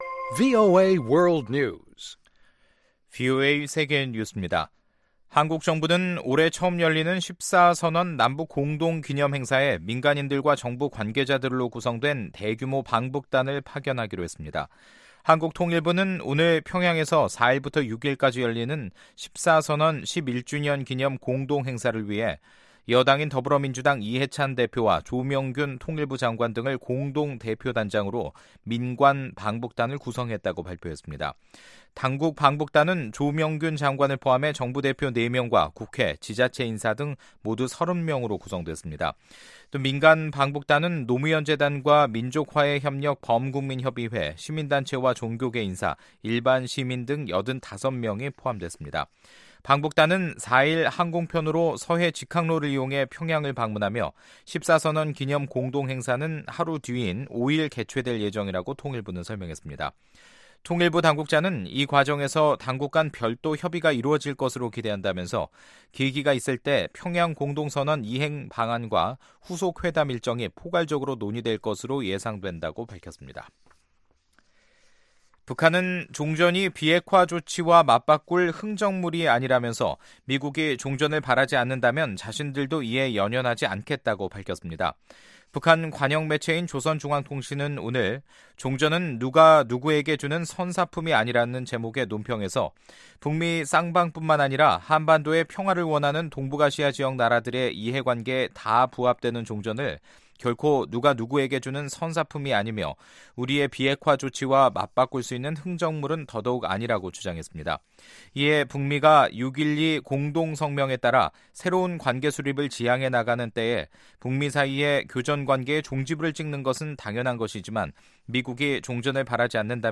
VOA 한국어 간판 뉴스 프로그램 '뉴스 투데이', 2018년 10월 2일 2부 방송입니다. 한국 국방부는 남북한이 1일 시작한 지뢰 제거 작업은 긴장 완화를 위한 시작이라고 밝혔습니다. 미국인 10명 가운데 8명은 북한의 비핵화 시 미국의 상응 조치로 북한과의 수교를 꼽았습니다.